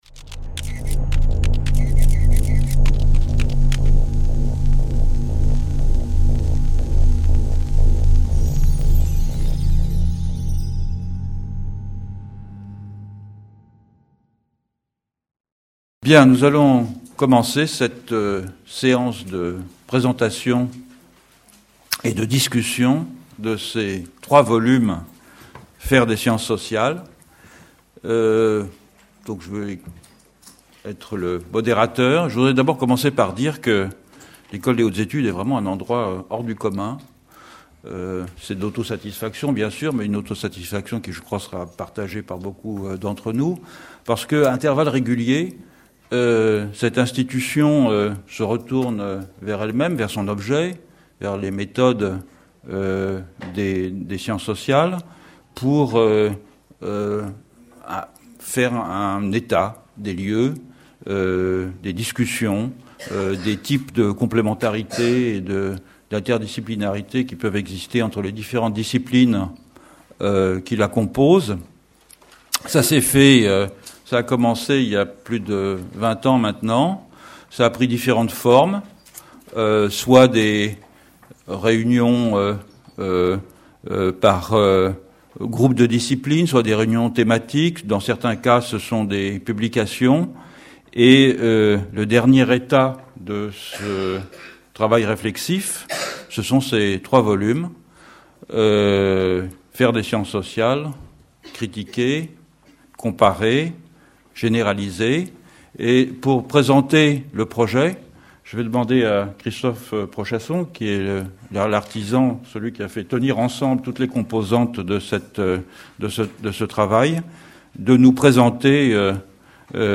Conférence organisée par les Éditions de l'EHESS Enregistré le lundi 3 décembre 2012 à l'Amphithéâtre de l'EHESS